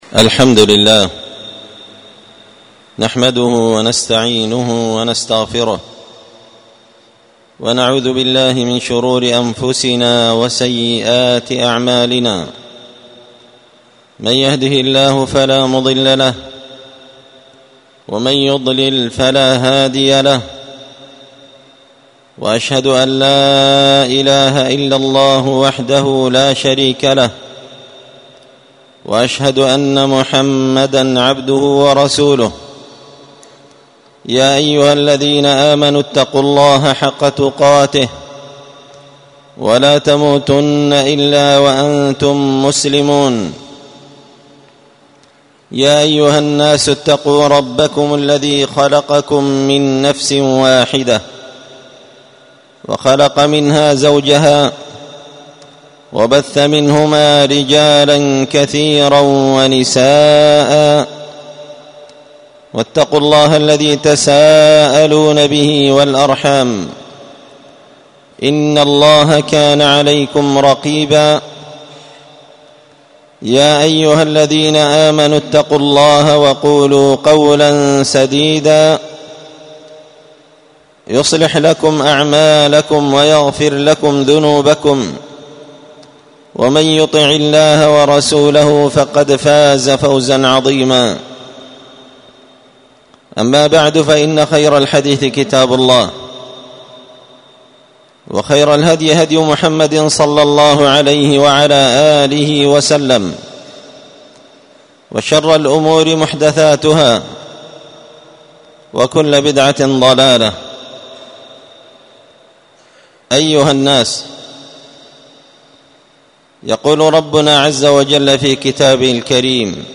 خطبة جمعة بعنوان
ألقيت هذه الخطبة بدار الحديث السلفية بمسجد الفرقان قشن -المهرة-اليمن تحميل